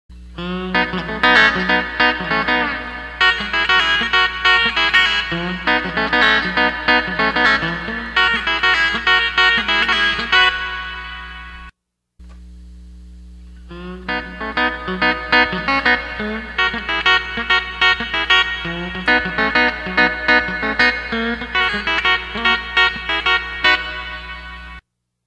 Steel Guitar Tab / Lessons
Tab 415 - E9 - Key Of B - Buck Trent Inspired Riff Tab